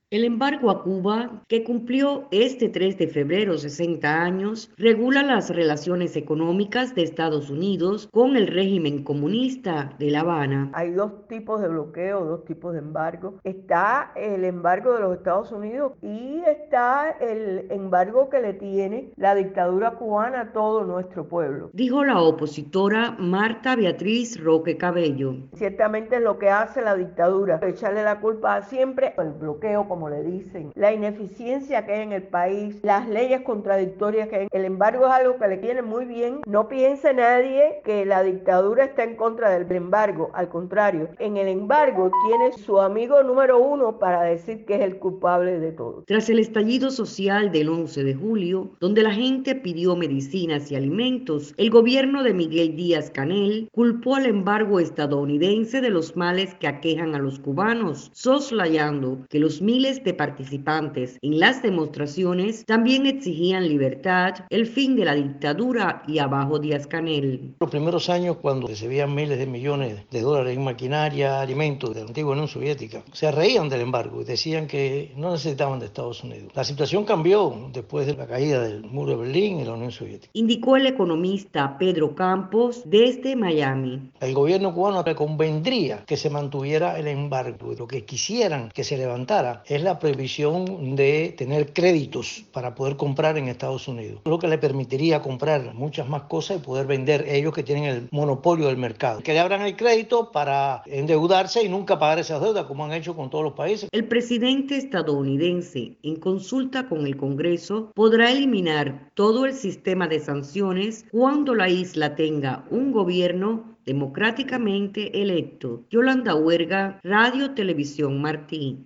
El embargo y los males que aquejan a Cuba: opinión de dos cubanos